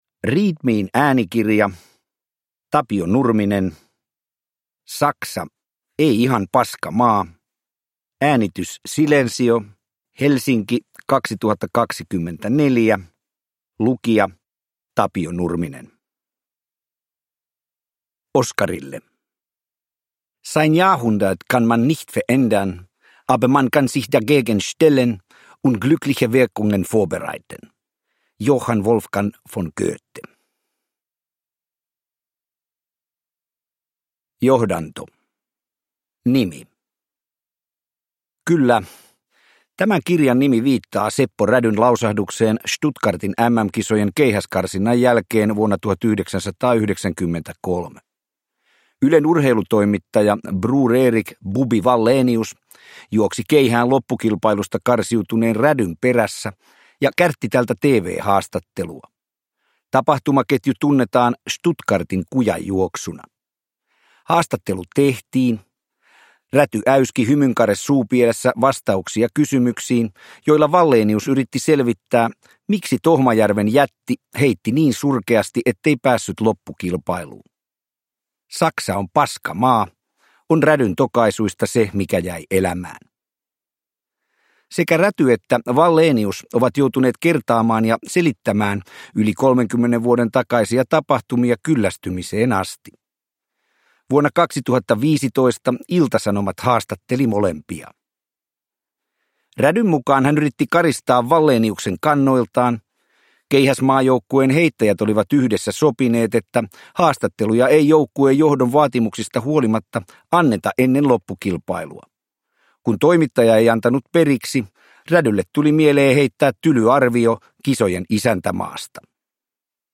Saksa - Ei ihan paska maa – Ljudbok